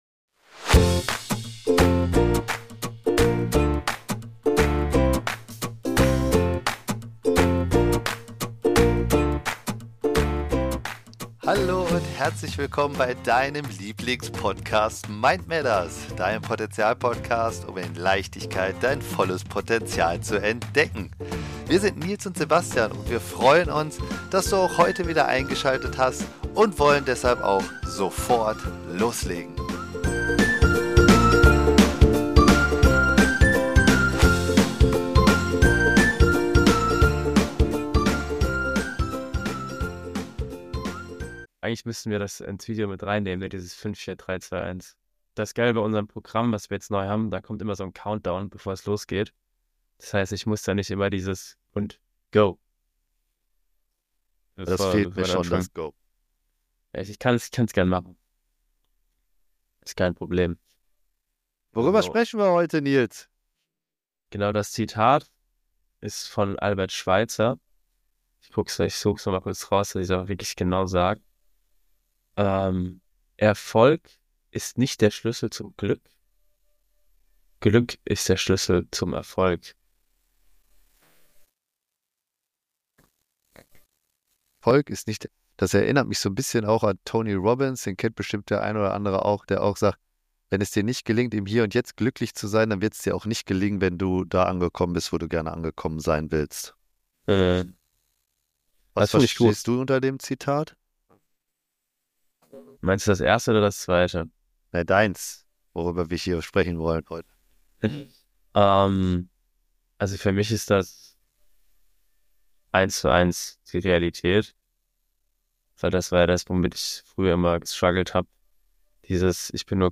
Die beiden Moderatoren ermutigen die Zuhörer, Glück als Grundlage für ihre Ziele zu betrachten und tägliche Praktiken zu entwickeln, um mehr Lebensfreude zu erfahren.